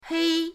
hei1.mp3